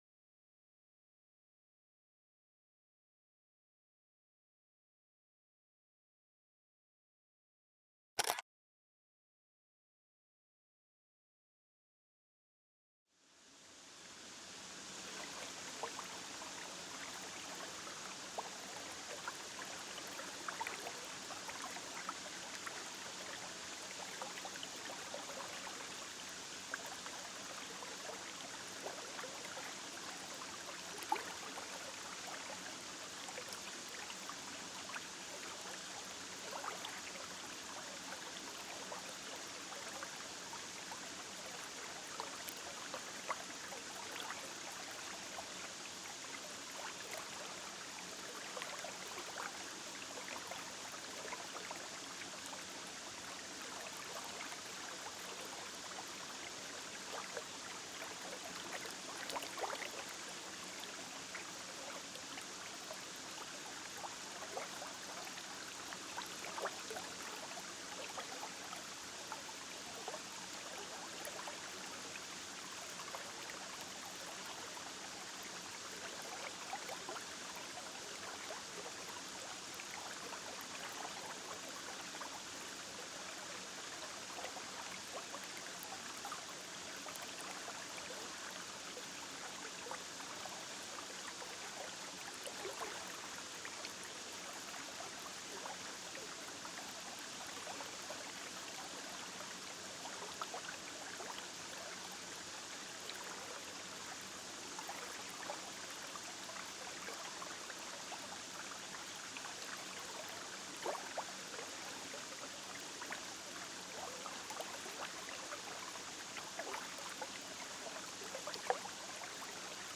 TIEFSTE ENTSPANNUNG: Sanfte Quelle-Stille mit Fließwasser